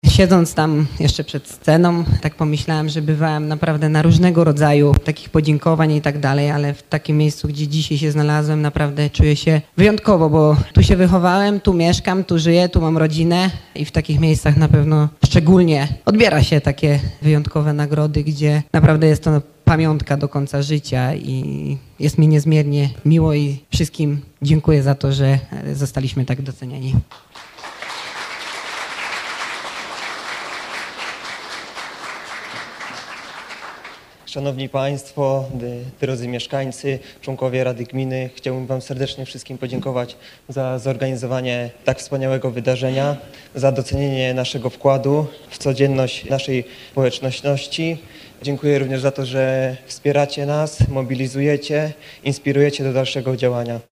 Bartosz Zmarzlik, pięciokrotny indywidualny mistrz świata na żużlu, oraz Daniel Sołtysiak, reprezentant Polski na Igrzyskach Olimpijskich w biegu sztafetowym 4×400 m, również nie kryli swojego wzruszenia z powodu otrzymania tak zaszczytnego tytułu.
NOWO-Zmarzlik-Soltysiak-honorowy_2.mp3